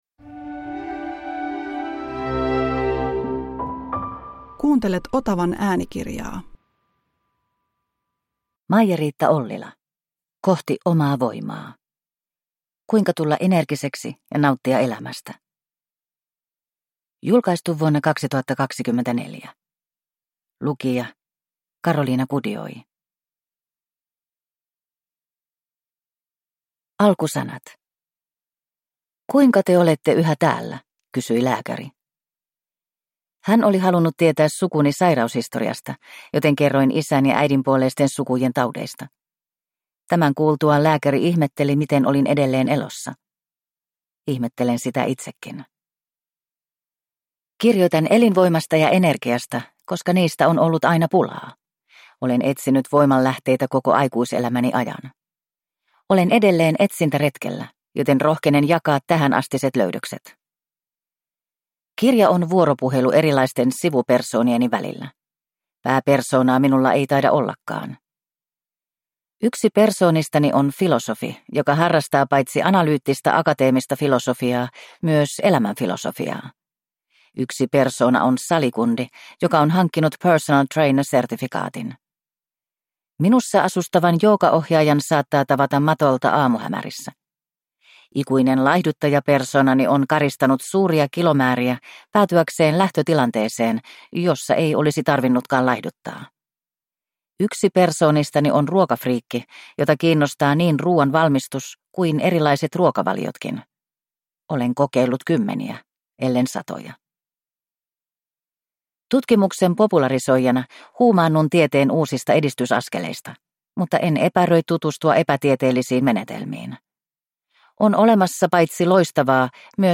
Kohti omaa voimaa – Ljudbok